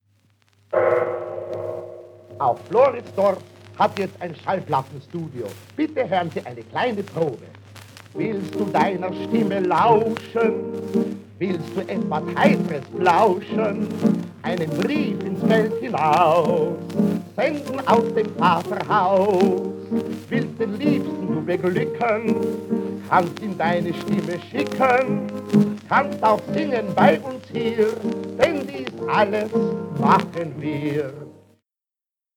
Werbeaufnahme für ein Wiener Tonstudio, undatiert, zwischen 1940 und 1945.